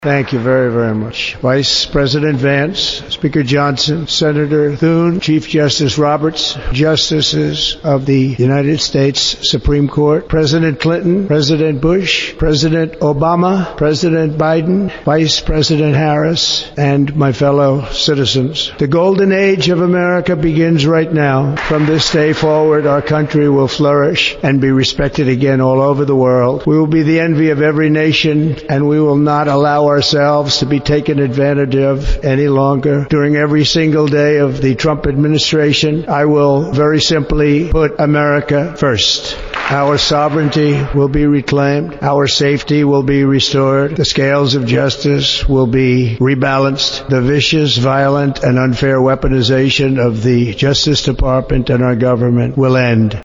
On Monday, President Donald J Trump took that oath of office and became America's 47th President. The President addressed America shortly after he was sworn in.